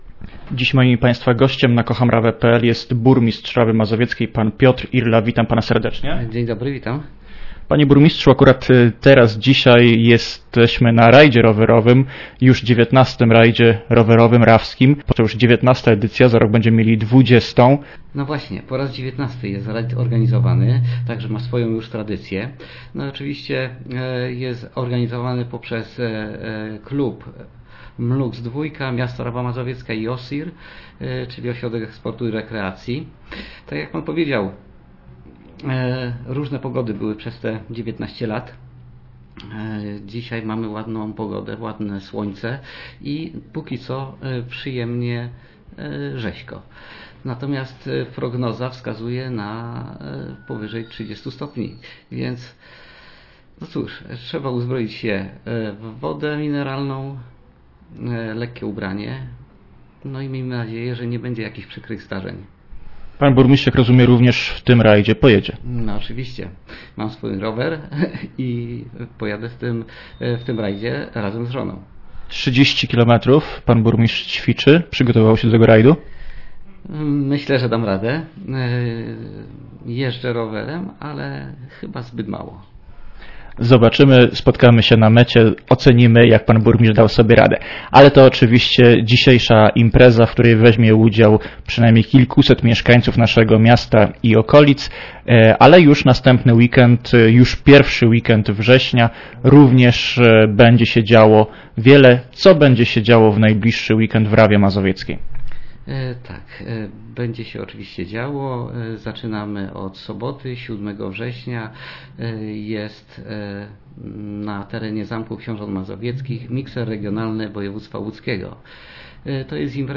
Poniżej rozmowa z Piotrem Irlą, burmistrzem Rawy Mazowieckiej o wydarzeniach, które w Rawie Mazowieckiej odbywać się będą w najbliższy weekend (7-8.09).